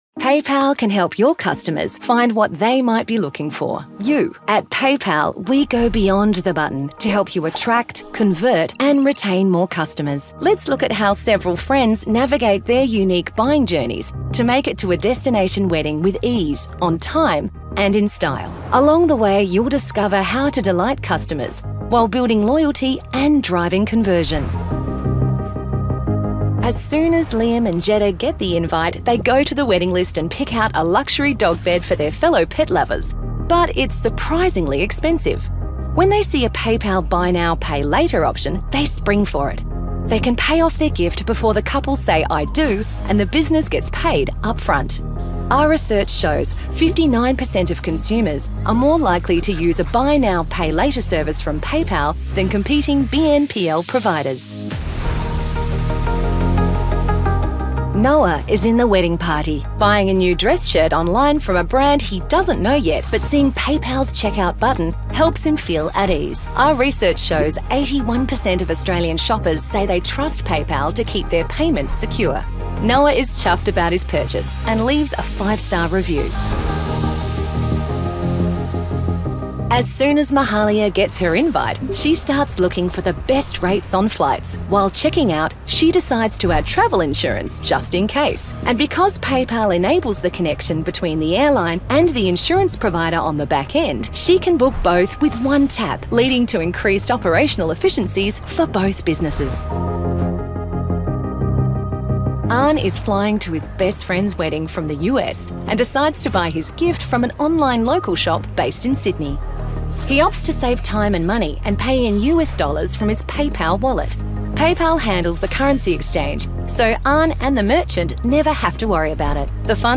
Englisch (Australien)
Kommerziell, Verspielt, Vielseitig
Erklärvideo